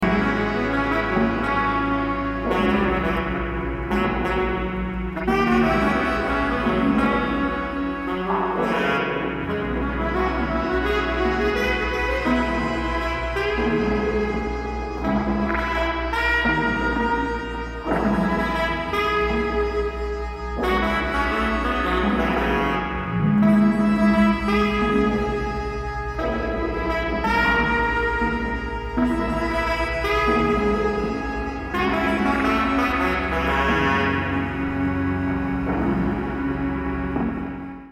スリリングなアフロビート、リラクシンなジャズファンク、フリージャズ、マンボ